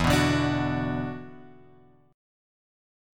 E7sus2 chord {0 2 0 x 3 2} chord